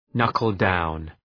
knuckle-down.mp3